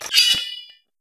SwordUnsheath.wav